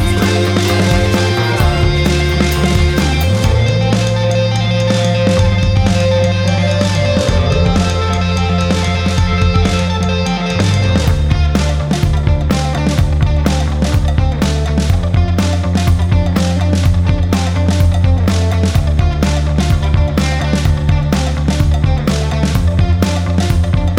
No Lead Guitar Rock 3:25 Buy £1.50